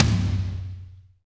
-DRY TOM 1-L.wav